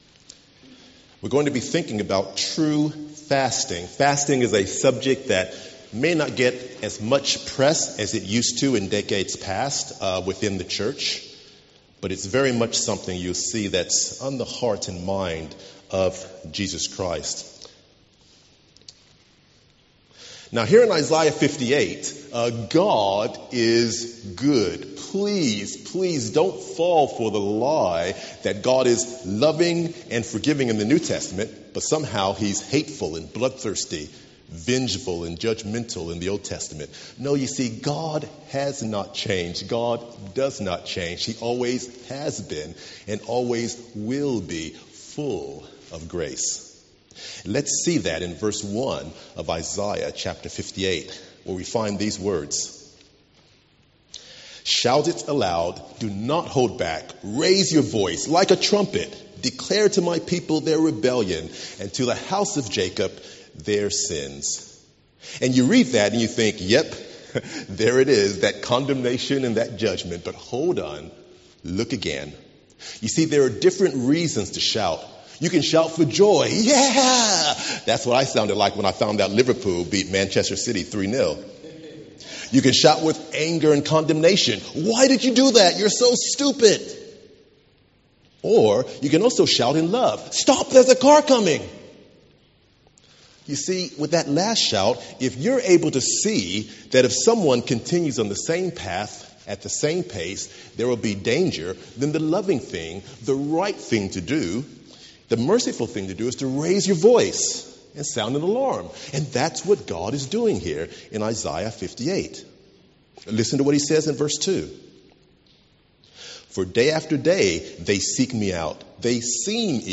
Today’s sermon is taken from Isaiah 58 and covers the subject of “True Fasting”.
An audio file of the service is now available to listen to.